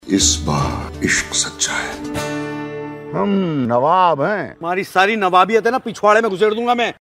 Dialogue Tones